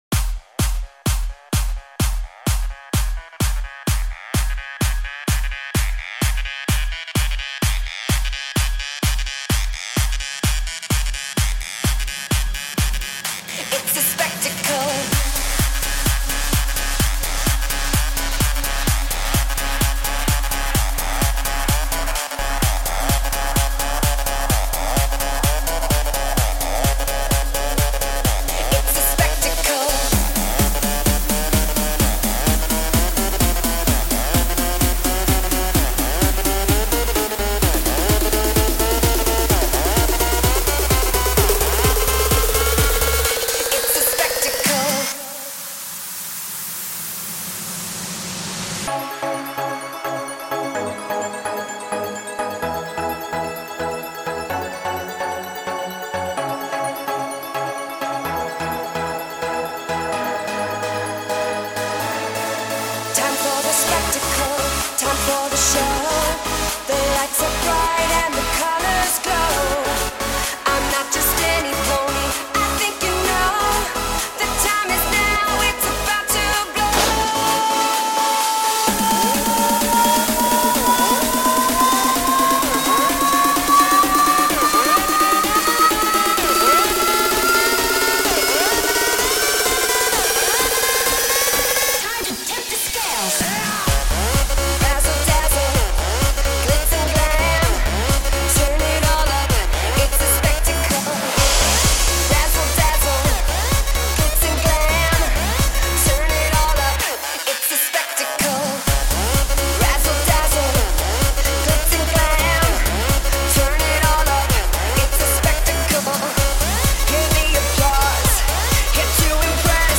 Genre: Big Room House/Melbourne Bounce
BPM: 128